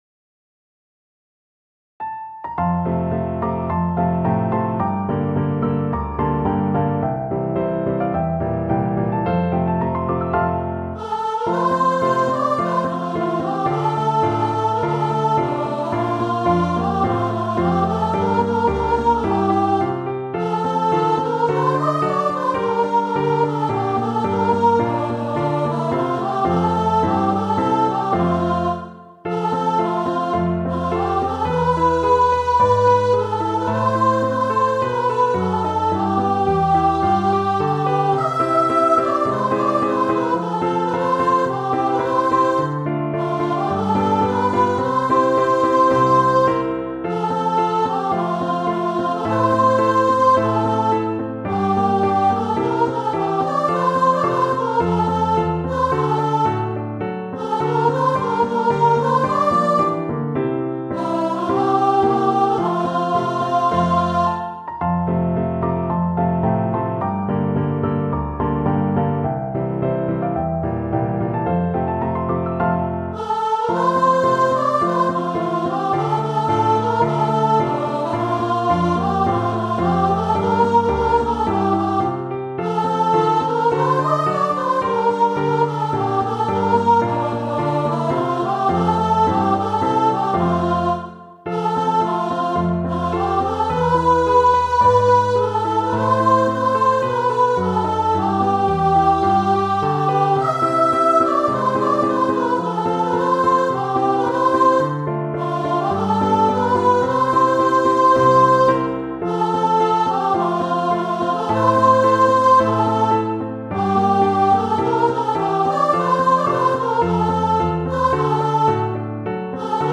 ピアノ版